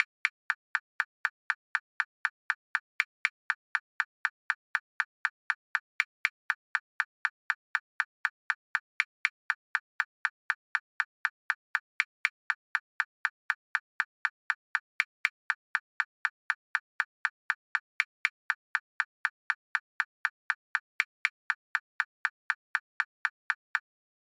Joint Stereo vs Stereo